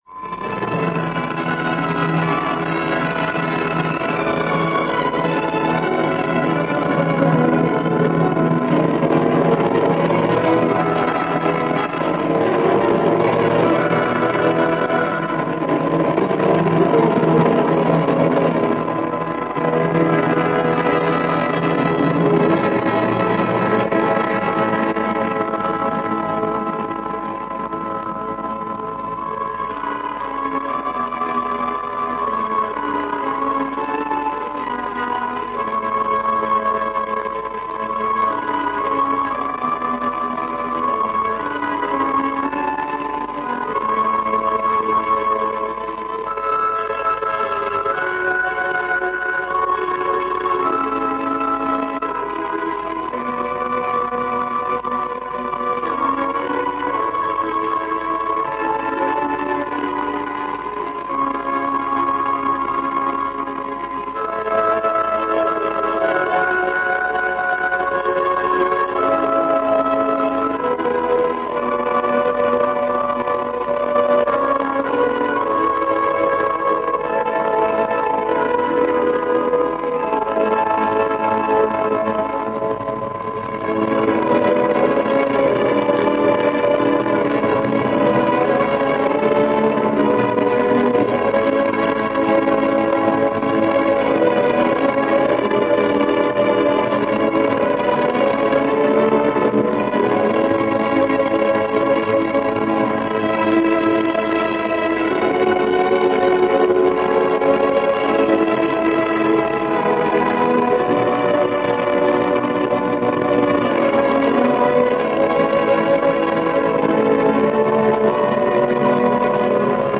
szimfonikus költeménye
Herbert von Karajan vezényel.